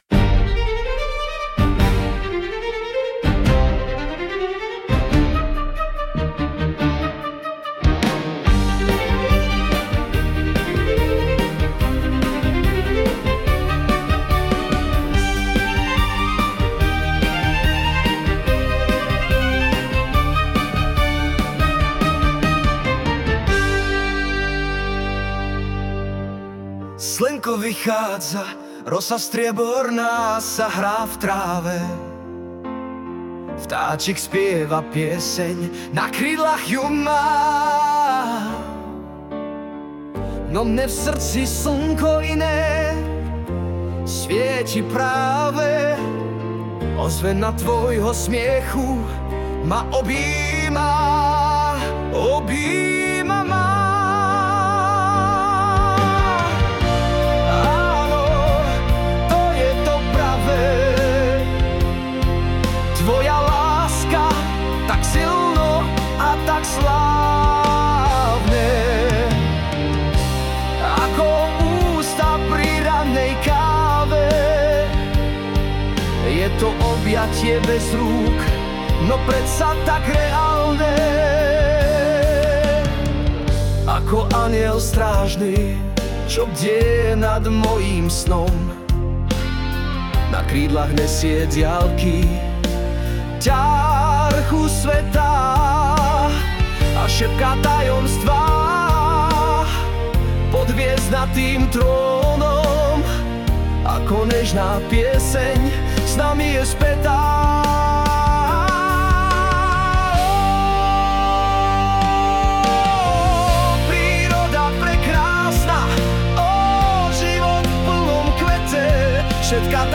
Hudba a spev AI
tohle je jak smyčcová dokonalost - tak ta dnes u mne vítězí zcela!